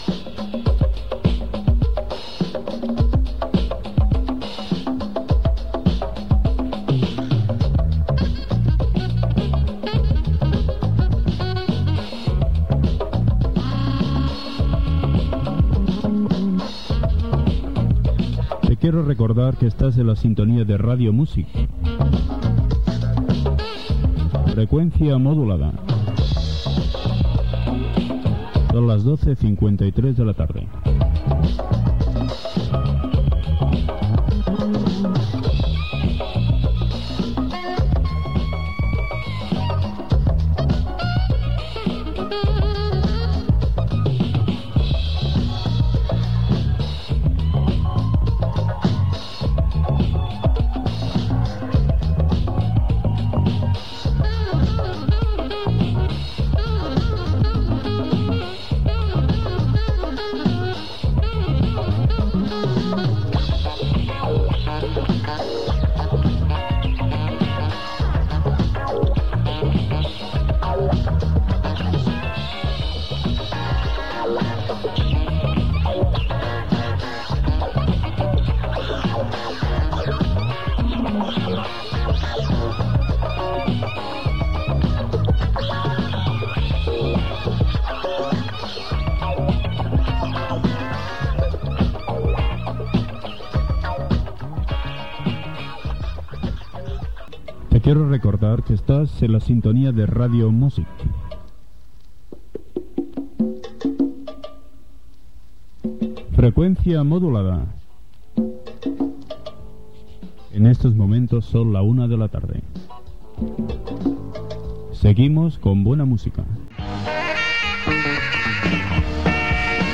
Temes musicals
Banda FM